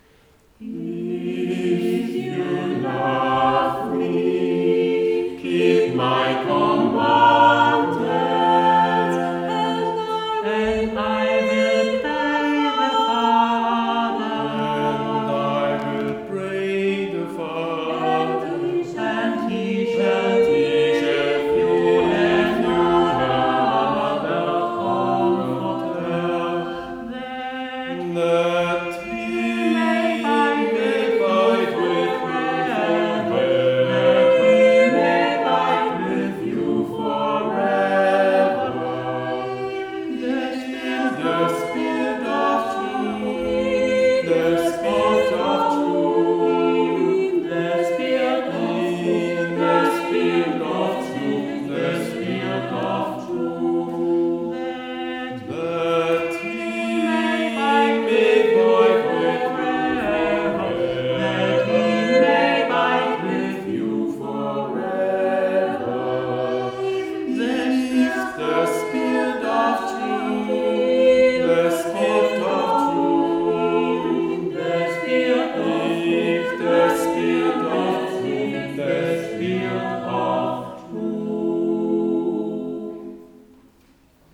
Sopran:
Alt:
Tenor:
Bass: